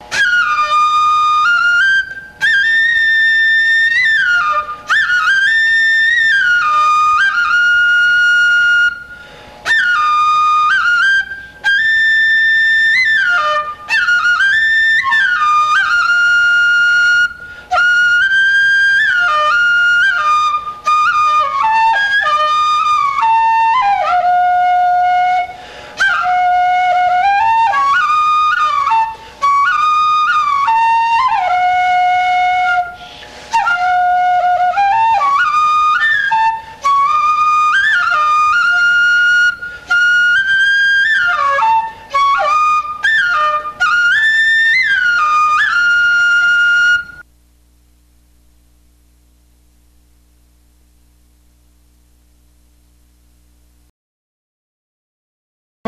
笛の音